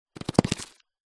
Все записи натуральные и четкие.
Мышь или крыса, бег по картонной коробке, испуганный